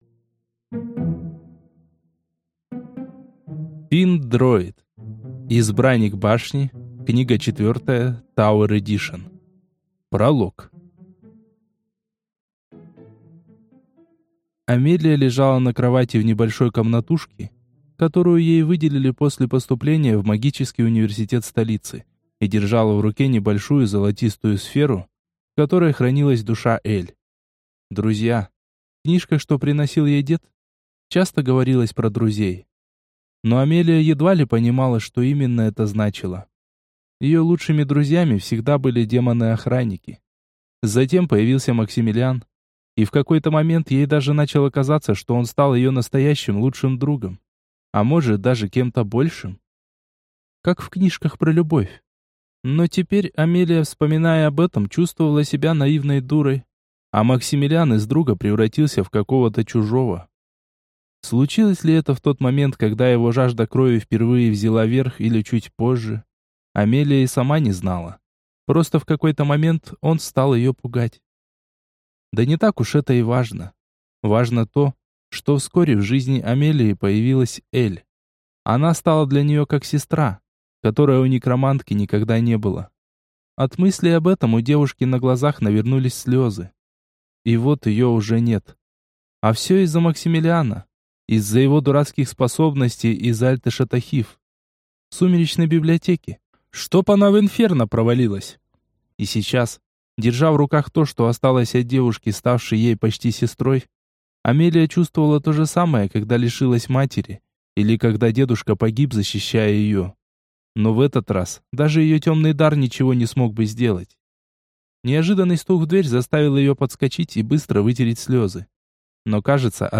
Аудиокнига Избранник Башни. Книга 4. Tower Edition | Библиотека аудиокниг